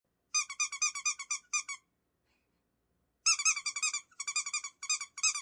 Download Squeaky Toy sound effect for free.
Squeaky Toy